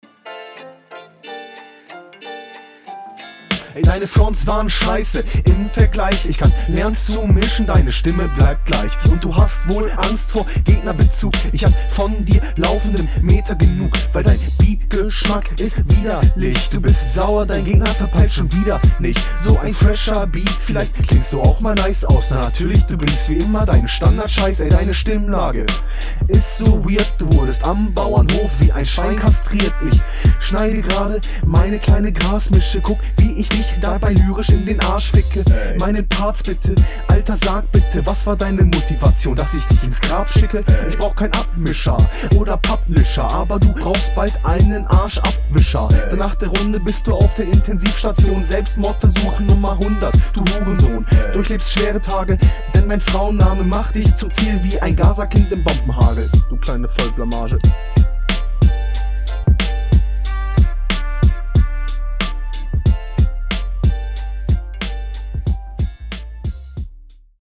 Du kommst auf deinem eigenen Beat nicht so gut wie auf dem deines Gegners.